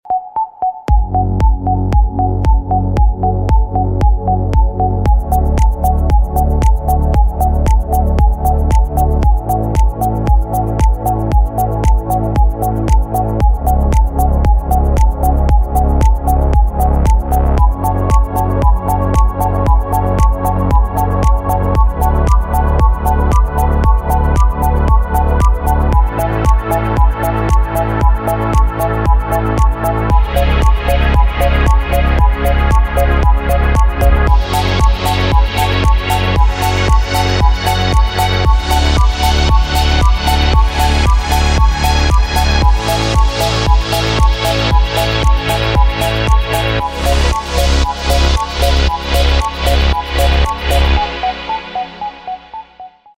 спокойные
без слов
Downtempo
инструментальные
нежные